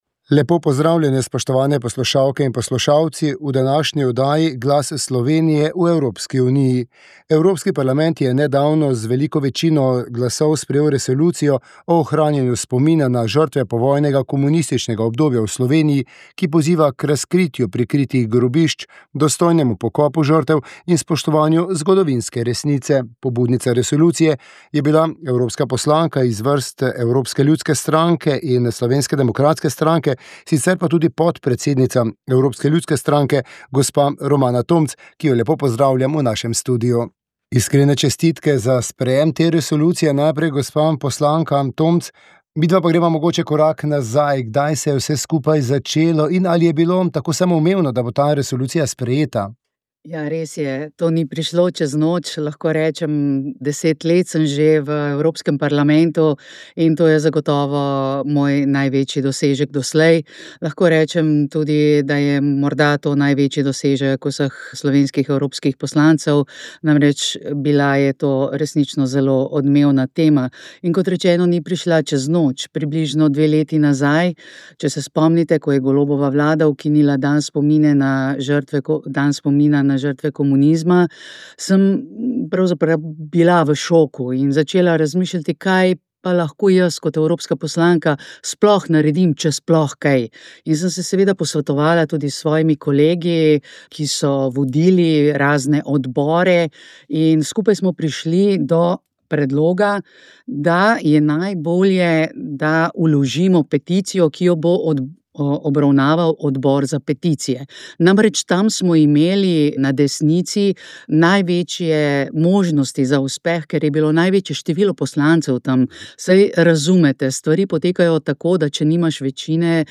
Klepet na kongresu